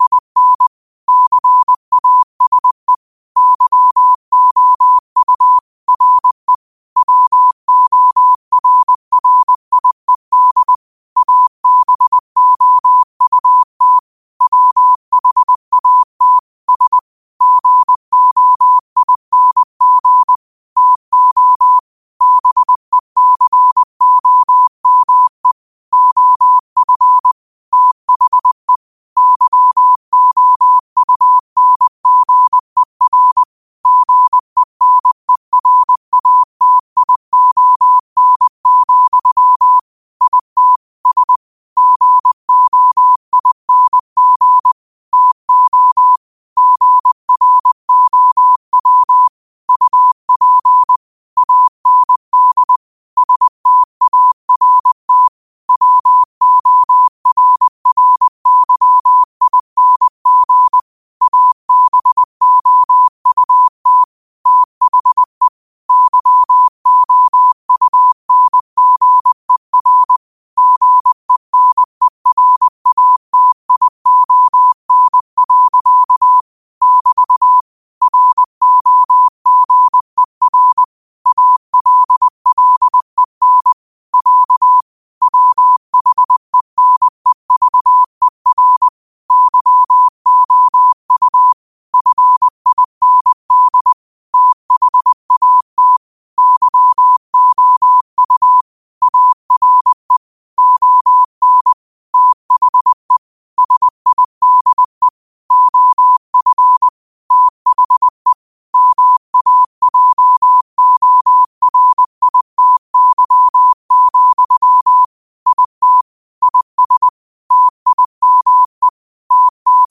morse code amateur radioDaily quotes in Morse Code at 20 Words per Minute.
Quotes for Wed, 30 Jul 2025 in Morse Code at 20 words per minute.